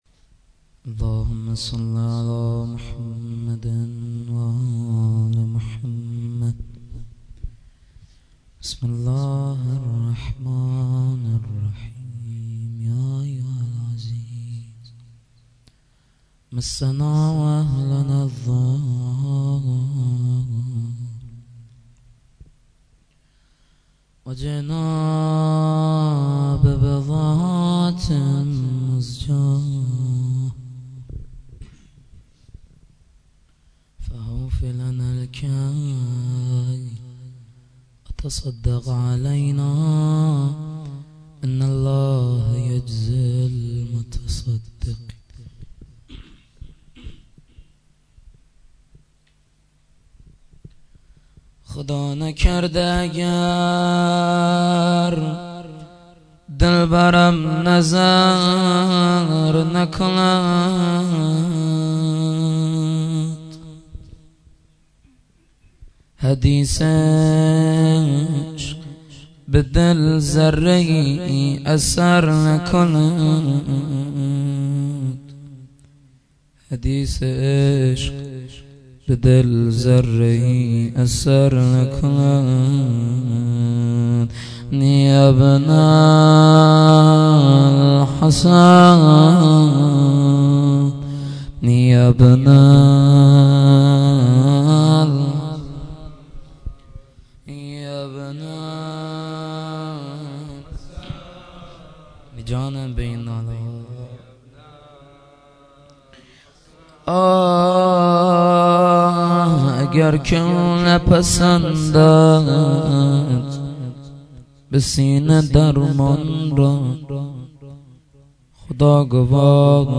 shahadat-emam-reza-92-rozeh-2.mp3